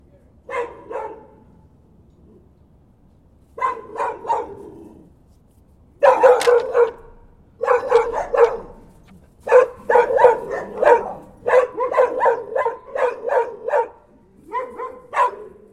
Chiens
dog_s.mp3